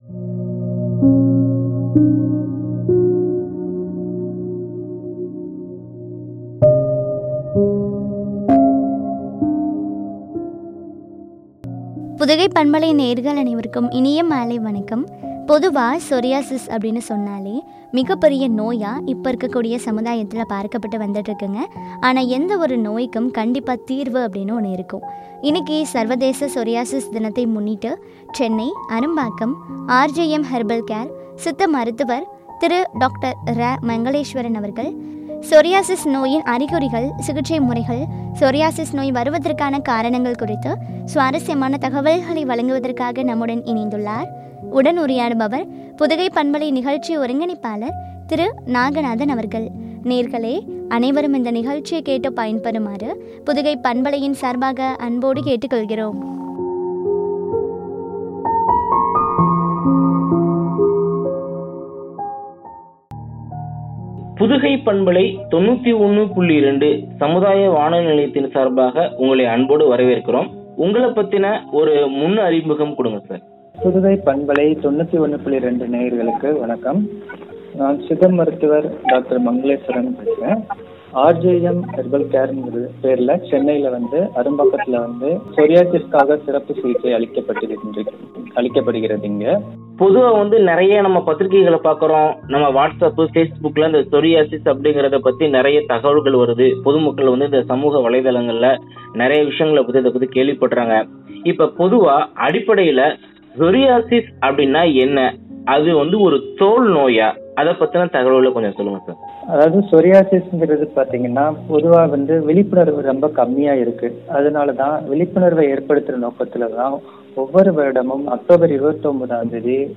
அறிகுறிகளும் குறித்து வழங்கிய உரையாடல்.